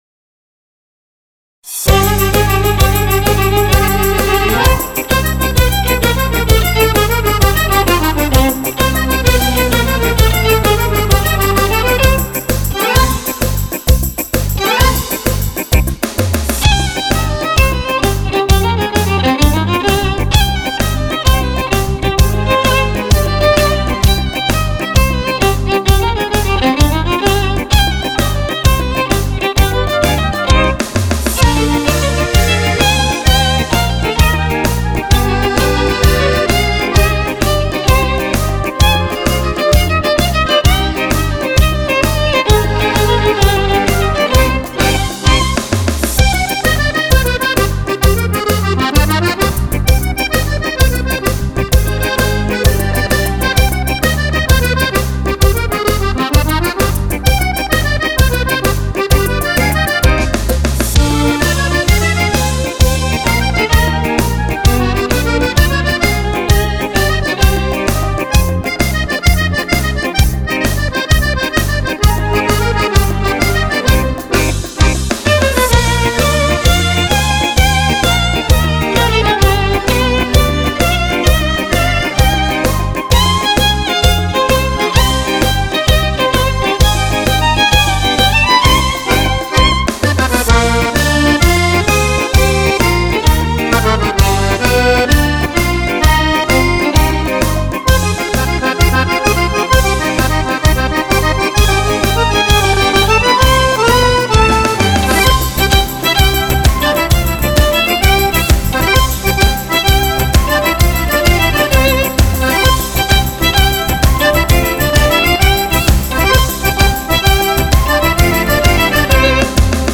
Tarantella
Tarantella per Fisarmonica e Violino